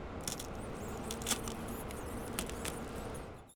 楼道场景4.ogg